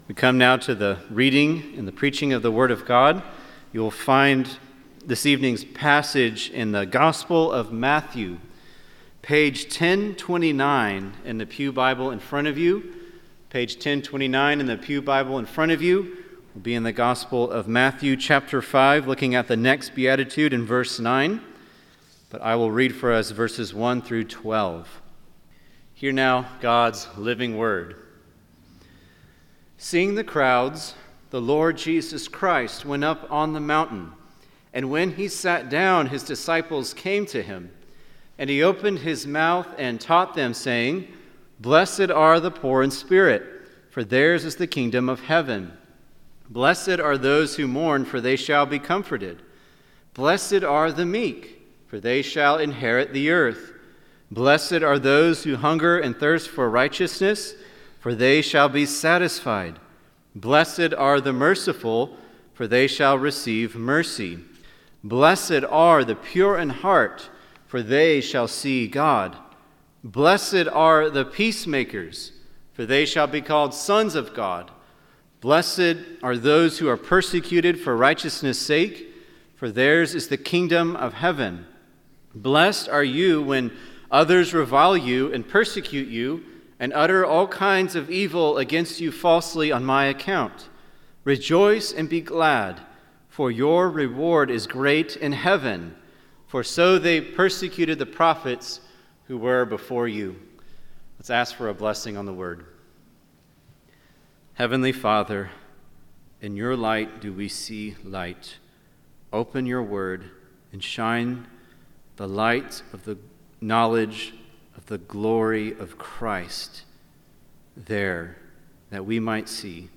preaches from Matthew 5:9 on the peacemaker’s disposition, vocation, and vindication.